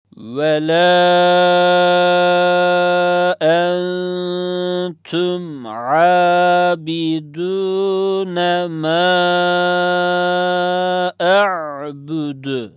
Üzerine konulduğu harfi dört elif miktarı uzatarak okutur